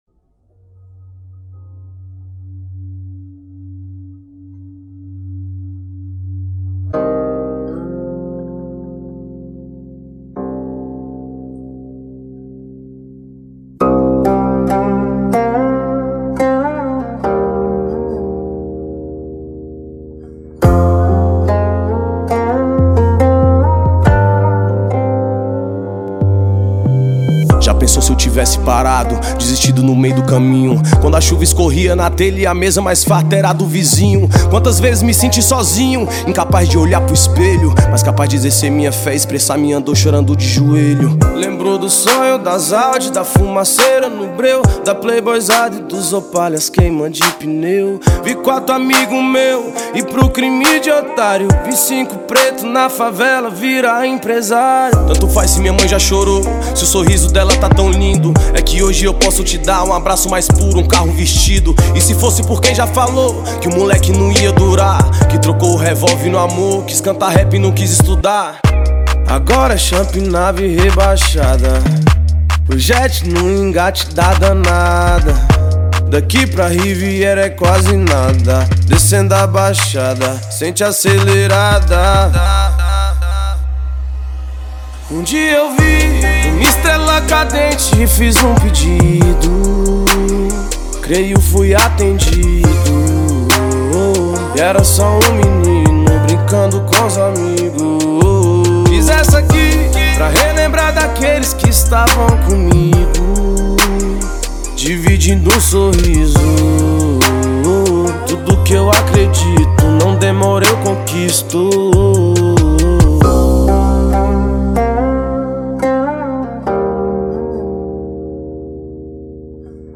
2025-03-17 19:27:11 Gênero: Rap Views